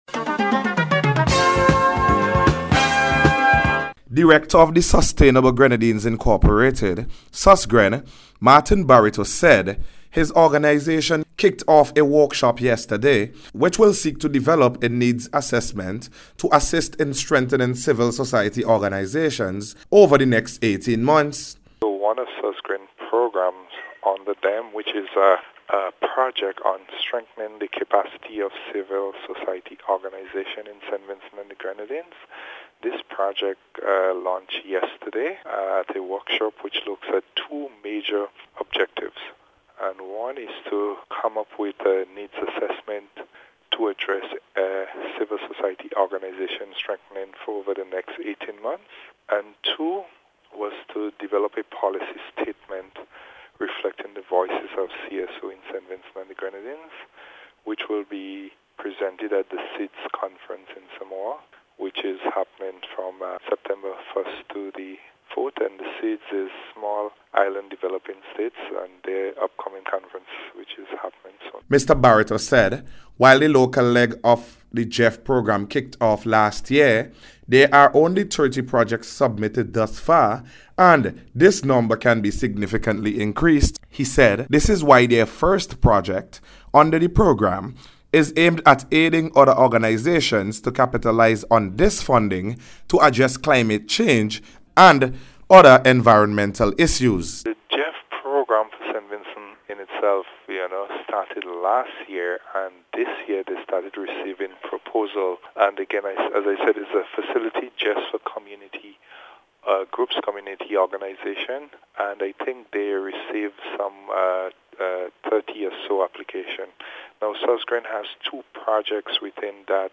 GEF-CAPACITY-BUILDING-REPORT.mp3